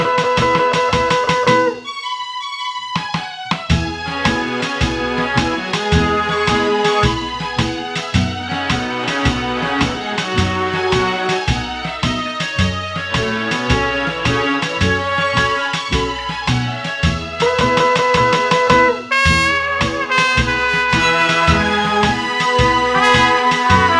no Backing Vocals Bollywood 4:31 Buy £1.50